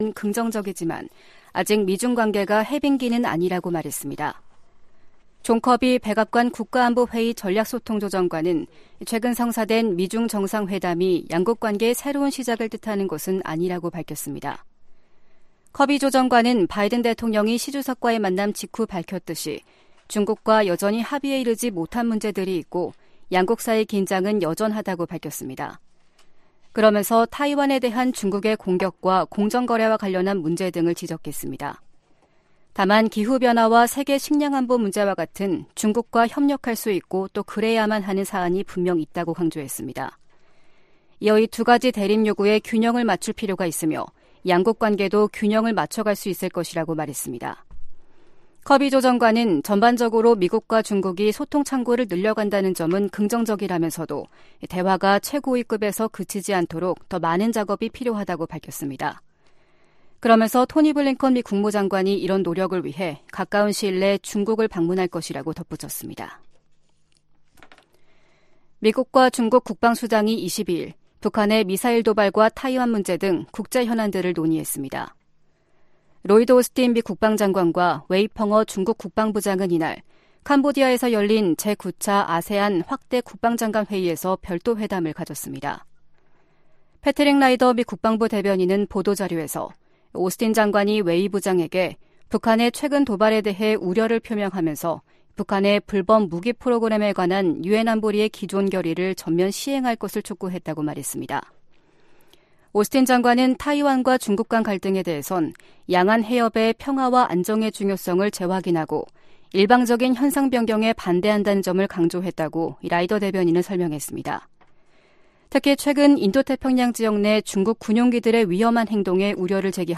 VOA 한국어 아침 뉴스 프로그램 '워싱턴 뉴스 광장' 2022년 11월 23일 방송입니다. 유엔 안보리가 북한의 대륙간탄도미사일(ICBM) 발사에 대응한 공개회의를 개최하고 북한을 규탄했습니다. 북한의 화성 17형 시험발사에 대응해 21일 소집된 유엔 안보리에서 중국과 러시아는 북한의 도발이 미국 때문이라는 주장을 반복했습니다.